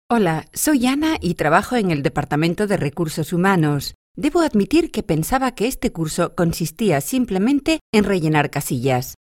Sprecherin spanisch. Werbesprecherin. Sprecherin für Sprachkurse.
kastilisch
Sprechprobe: eLearning (Muttersprache):
Spanish female voice over talent.